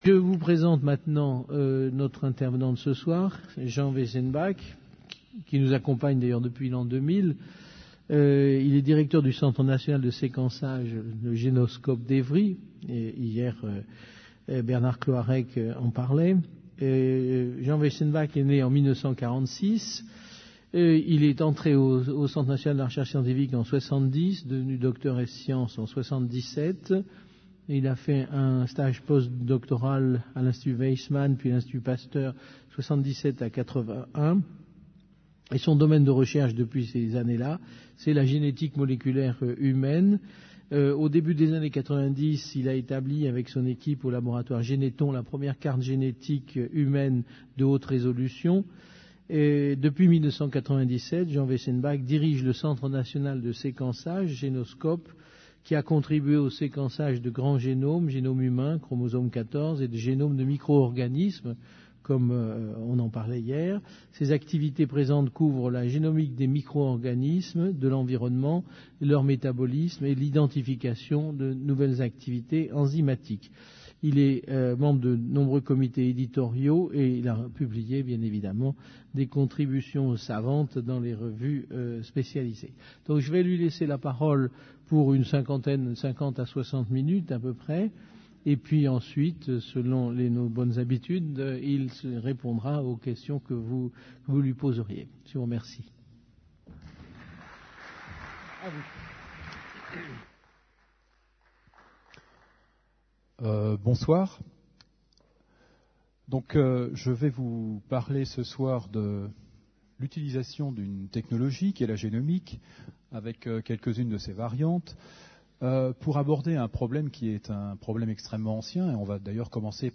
Une conférence du cycle : Qu'est ce que la vie ?